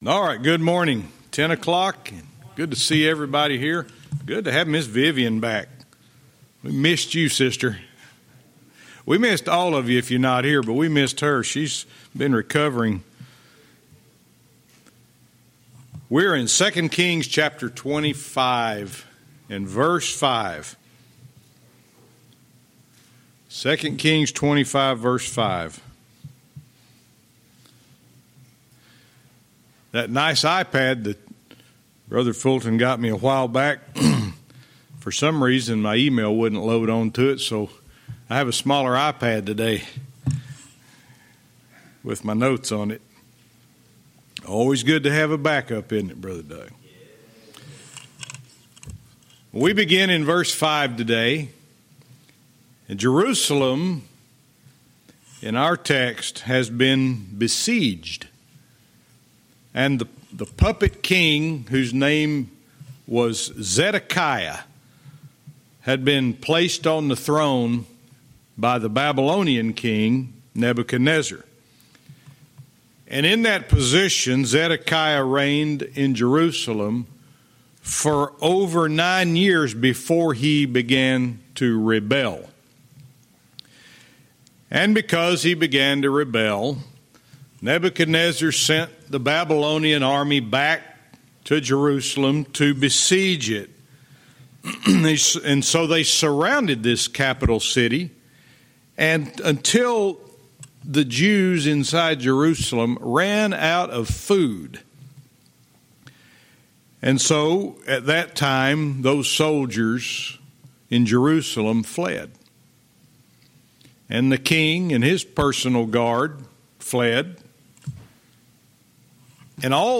Verse by verse teaching - 2 Kings 25:5-10